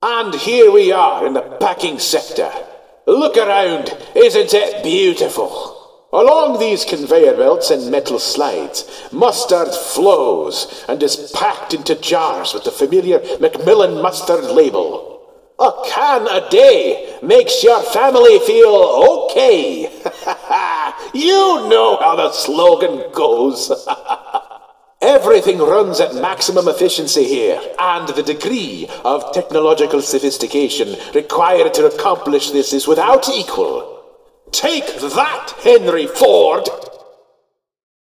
mustard_04_packing.ogg